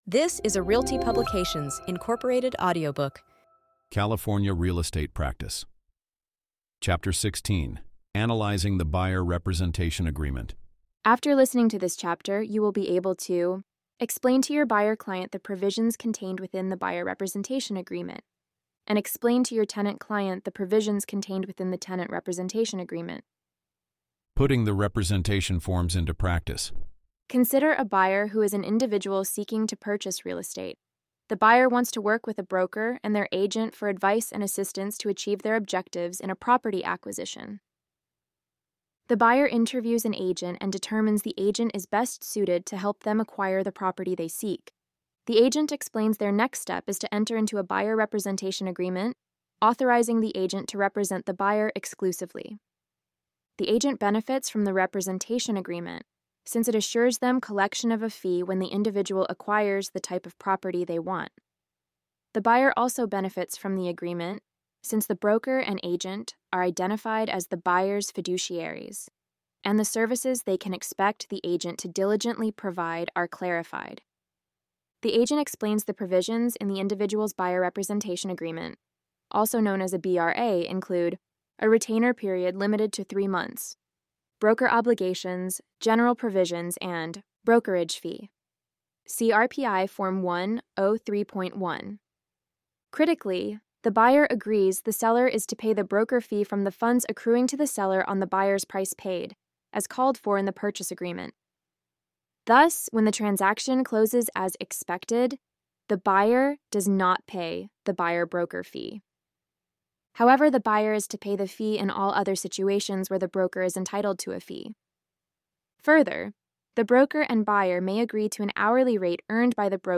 Follow along with an audio reading of this article adapted as a chapter from our upcoming Real Estate Practice course update.